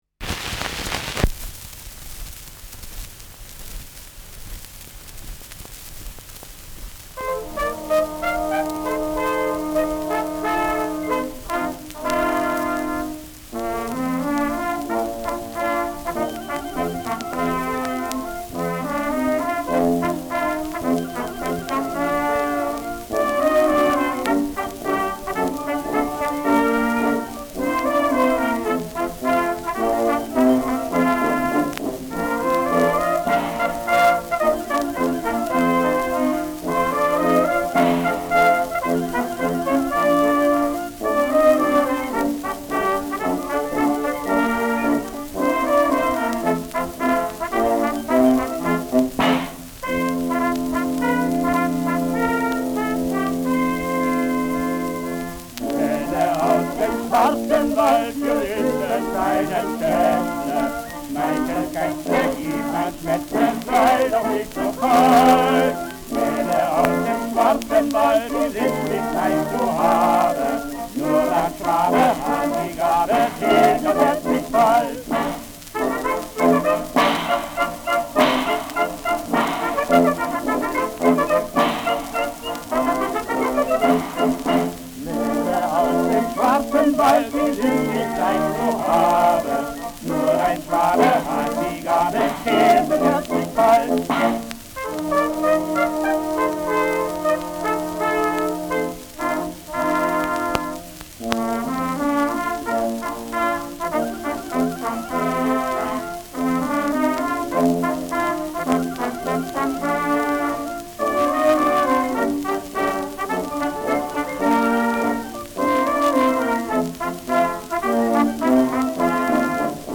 Schellackplatte
Vereinzelt leichtes Knacken : Pfeifen in Auslaufrille